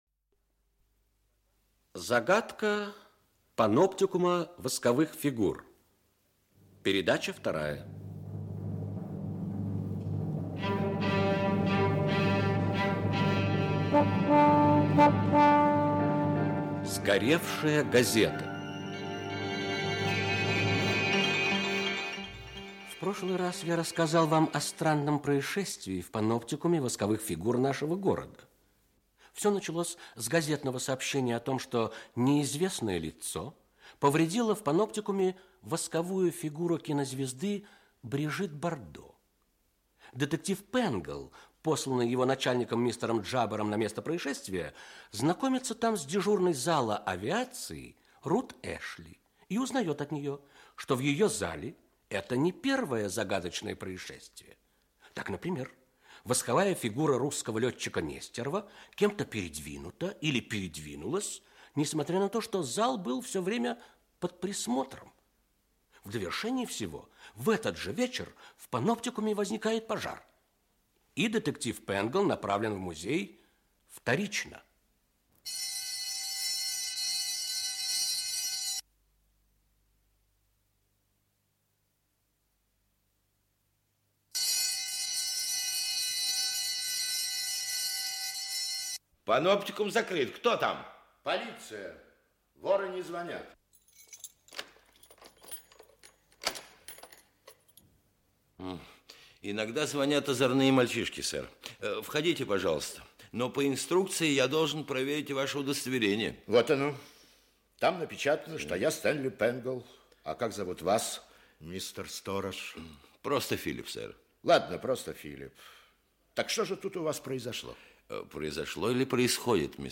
Аудиокнига Загадка паноптикума восковых фигур. Часть 2. Сгоревшая газета | Библиотека аудиокниг